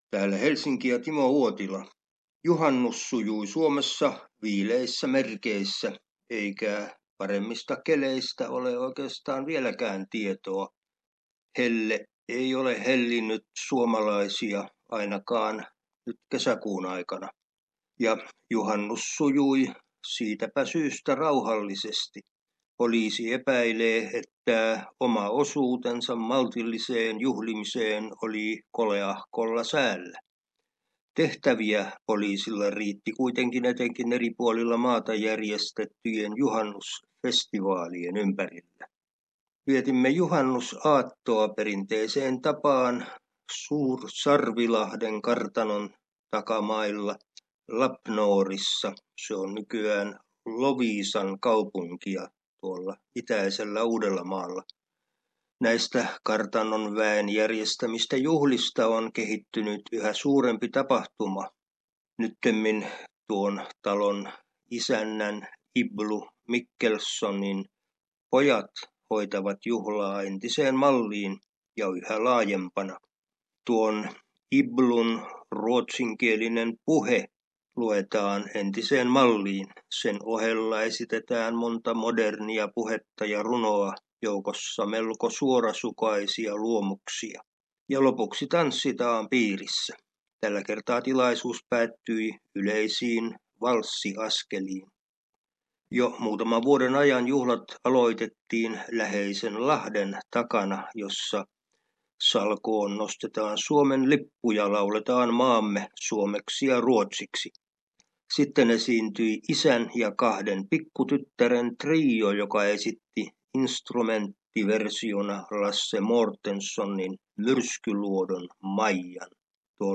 Suomen ajankohtaisraportti 29.6.17